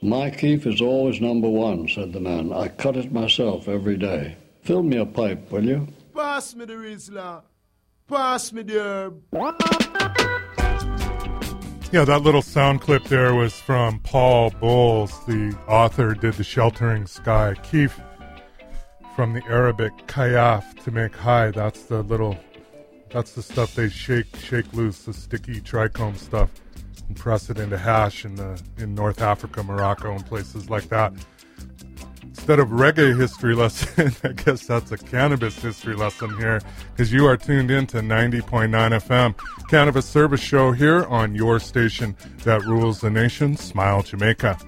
Kif – Author Paul Bowles, who lived in North Africa, talks to the kif seller.